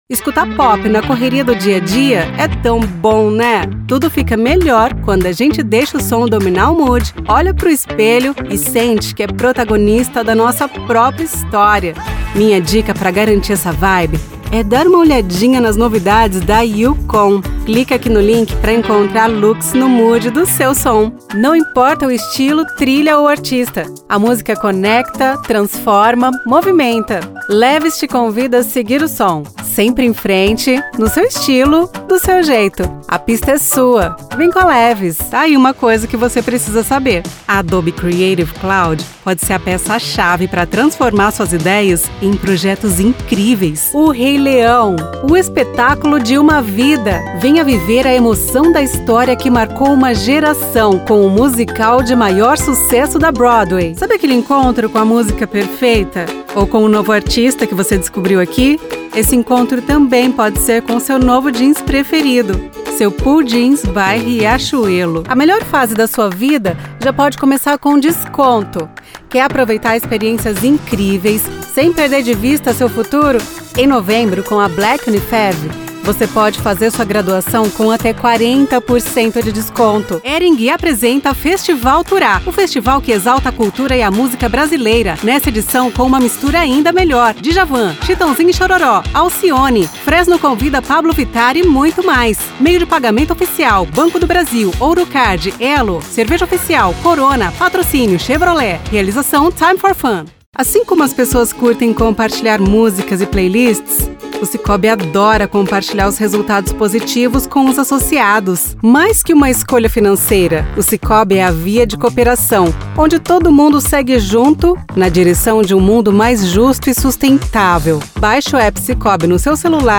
Never any Artificial Voices used, unlike other sites.
Female
Television Spots